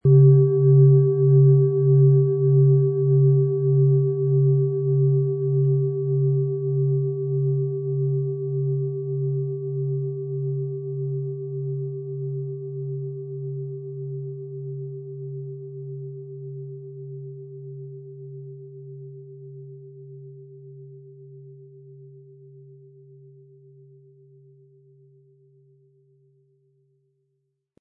OM Ton
Es ist eine von Hand geschmiedete Klangschale, die in alter Tradition in Asien von Hand gefertigt wurde.
MaterialBronze